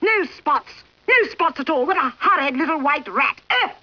Voice: Betty Lou Gerson